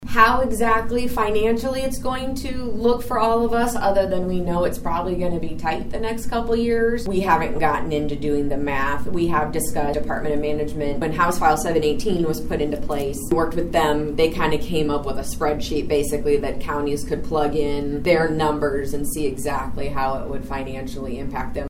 Flint says it’s still very early in the process to find out more information about proposed legislation that could greatly change the property tax system in Iowa.